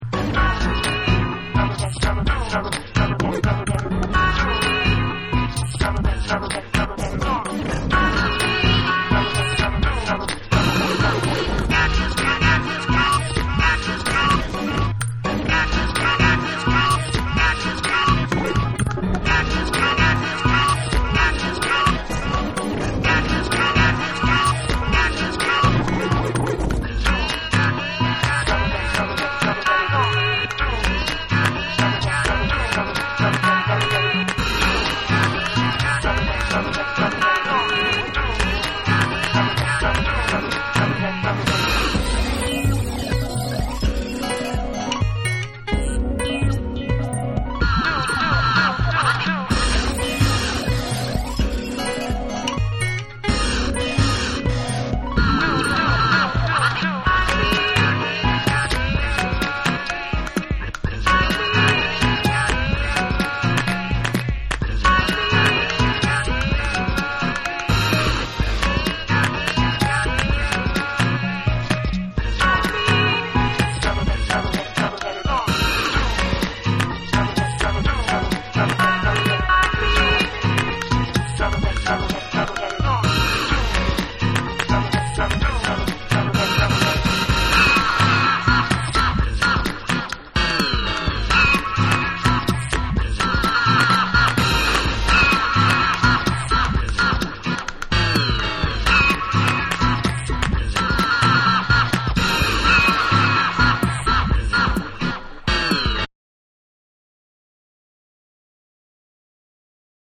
ブレイクを挟んでレゲエ・ブレイクに転調するナイスな仕上がり
BREAKBEATS